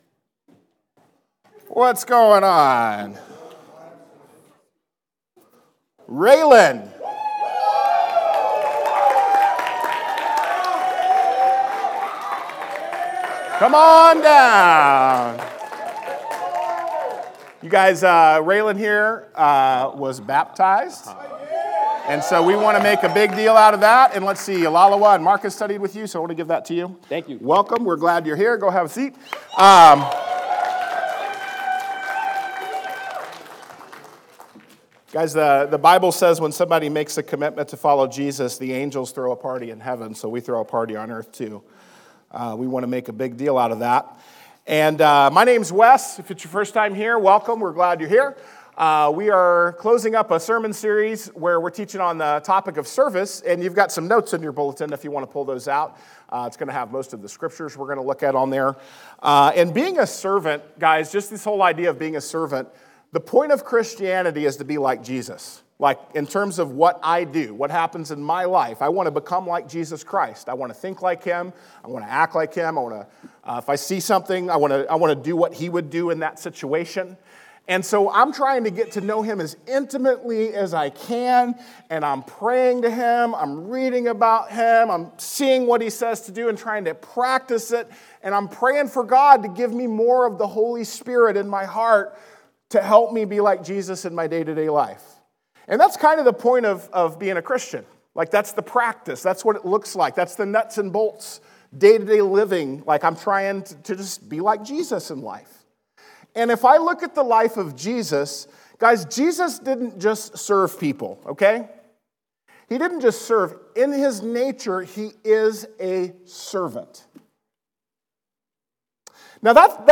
Lesson presented at The Crossings Church Collinsville in Collinsville, IL – a non-denominational church that meets Sunday mornings at 2002 Mall Street, Collinsville, IL just outside of St. Louis.